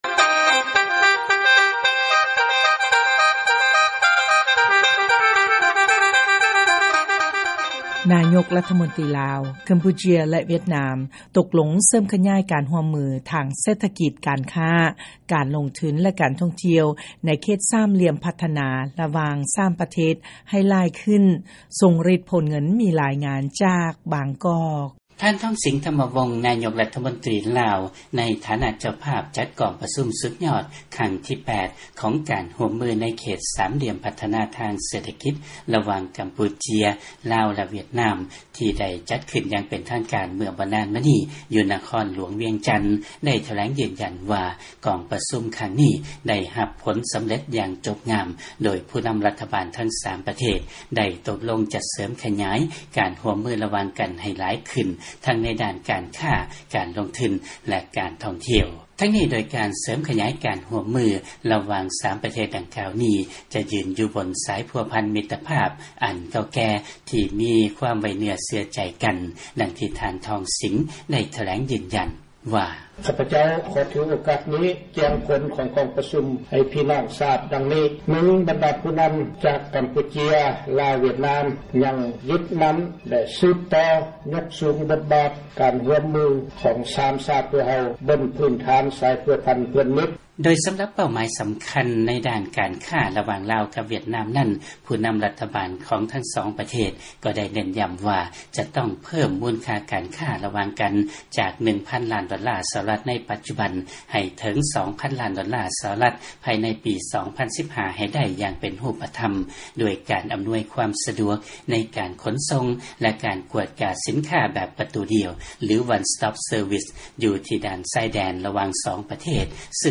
ຟັງລາຍງານ ນາຍົກ ຈາກ ລາວ ກຳປູເຈຍ ແລະ ຫວຽດນາມ ຕົກລົງກັນ ຈະເສີມຂະຫຍາຍການຮ່ວມມື ທາງເສດຖະກິດ ໃນ 3 ດ້ານ.